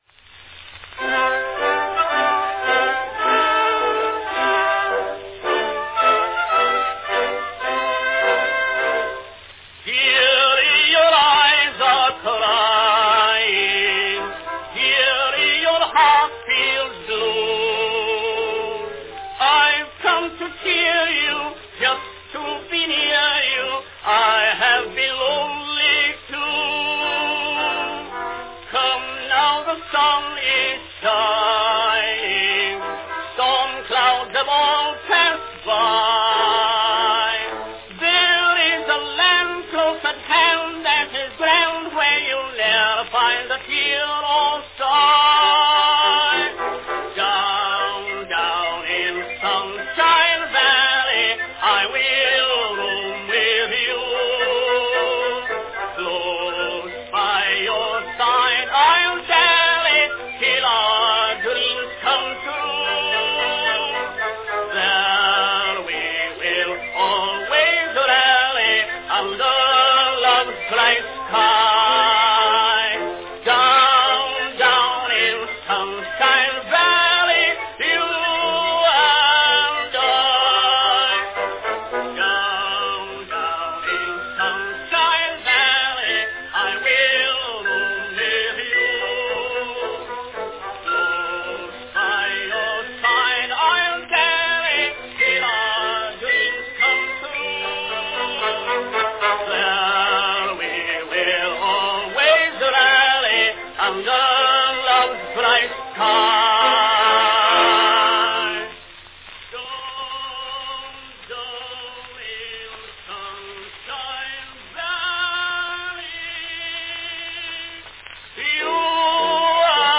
Category March song
Announcement None